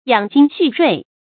注音：ㄧㄤˇ ㄐㄧㄥ ㄒㄩˋ ㄖㄨㄟˋ
養精蓄銳的讀法